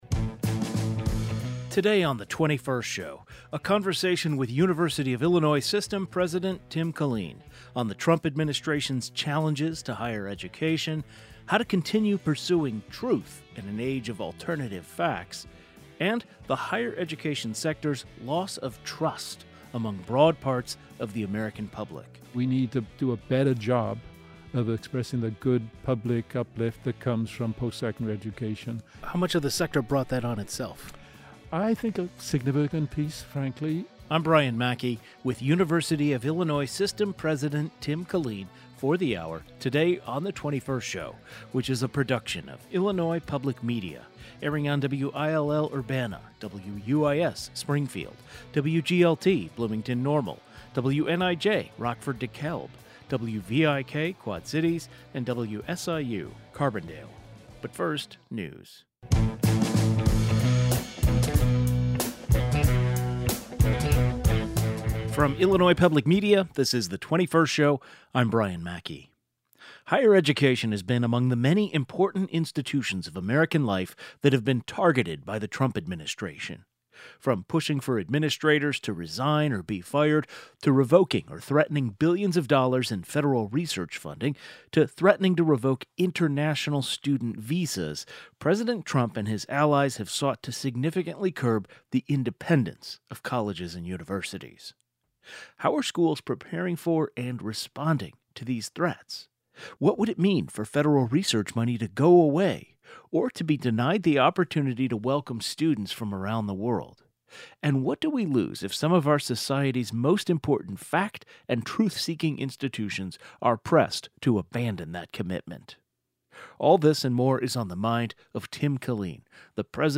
University of Illinois System President Tim Killeen discusses working with the federal government including threats to revoke the visas of internation students and losing funding for research. He also gives his take on academic freedom and the value of a university education in this day and age. The 21st Show is Illinois' statewide weekday public radio talk show, connecting Illinois and bringing you the news, culture, and stories that matter to the 21st state.